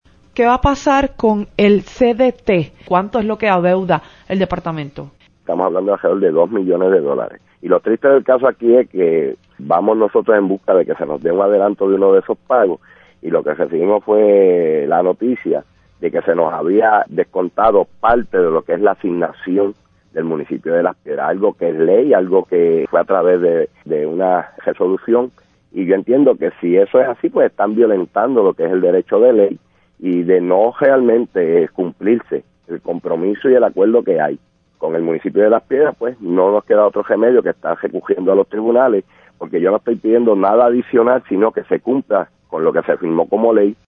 En Tras la Noticia, el ejecutivo denunció que el Gobierno ha incurrido en el impago de los fondos asignados -mediante ley- el pasado cuatrienio al centro hospitalario.
Escuche las palabras del Alcalde